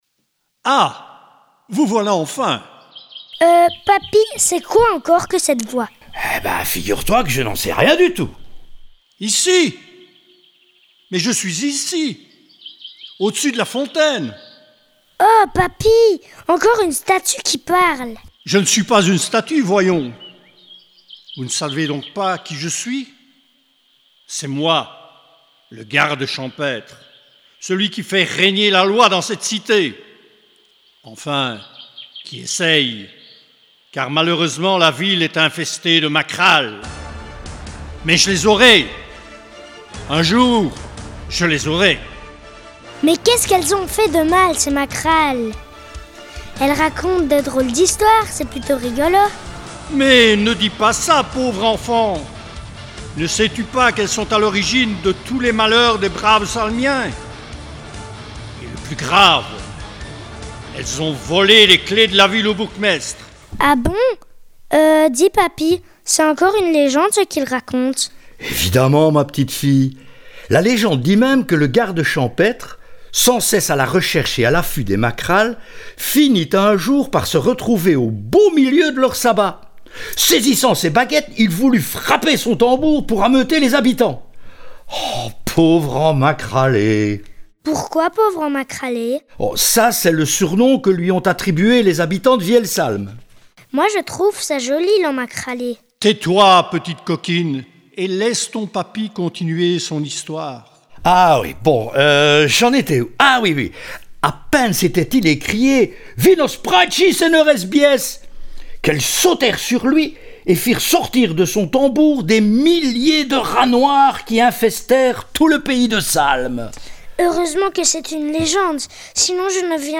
La balade est balisée et commence au syndicat d’initiative, laissez-vous guider par Capucine et son grand-père!